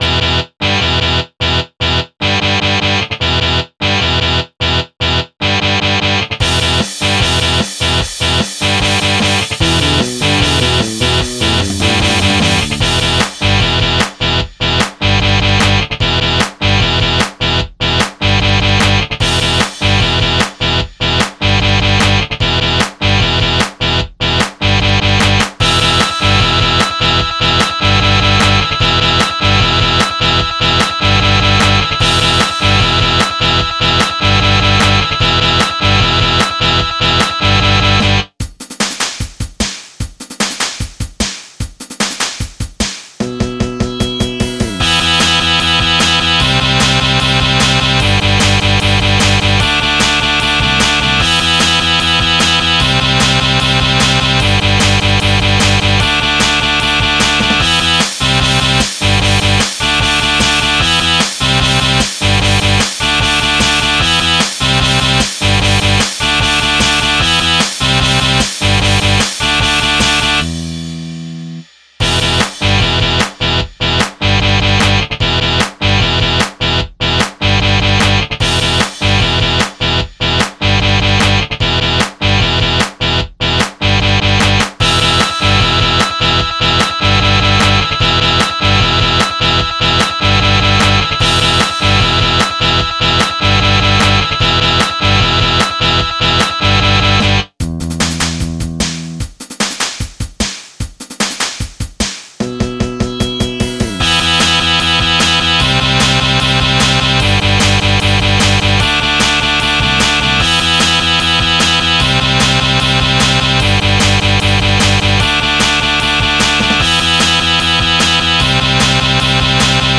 MIDI 59.18 KB MP3 (Converted) 3.4 MB MIDI-XML Sheet Music